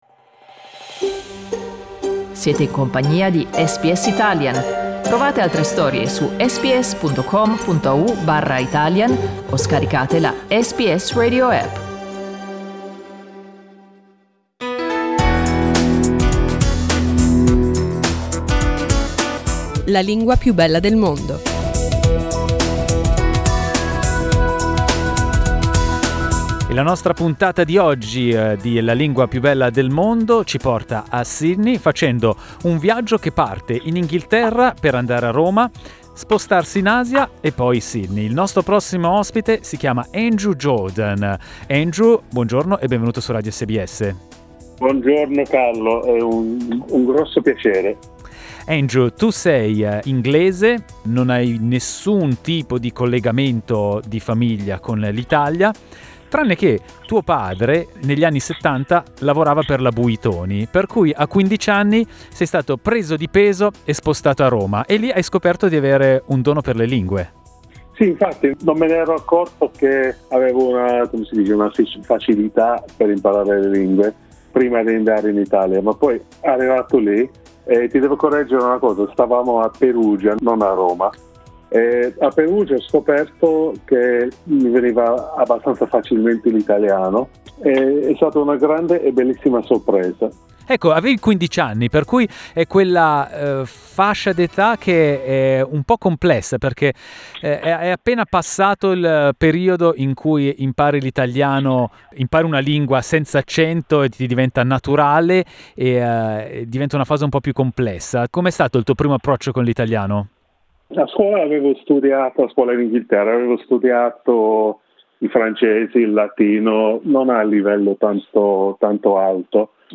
Ascoltate la sua storia raccontata a SBS.